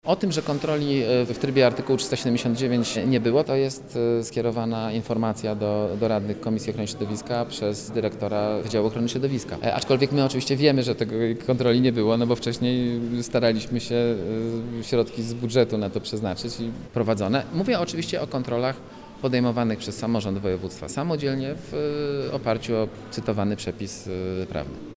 Zapytaliśmy się radnego sejmiku skąd pewność, że tych kontroli nie było.